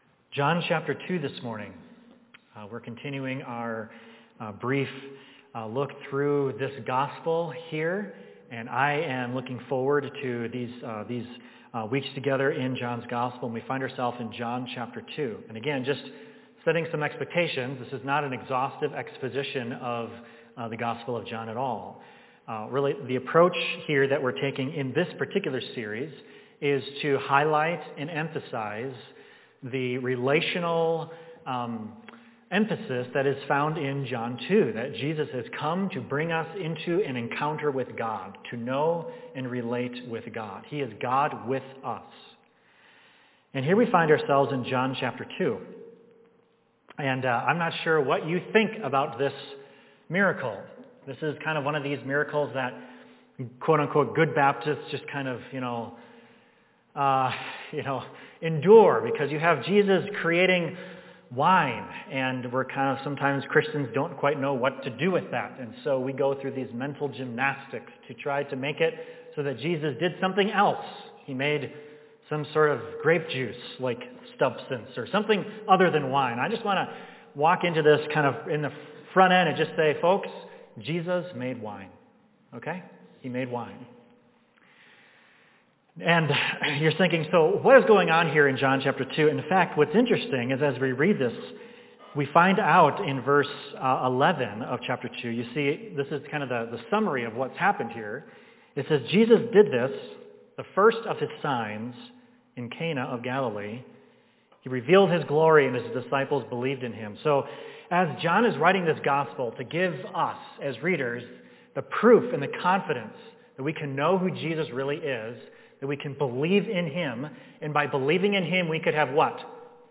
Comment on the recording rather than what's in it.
Service: Sunday Morning In Romans 8:28-30 we learn two promises that provide Christians with a joy that cannot be taken away (John 16:22).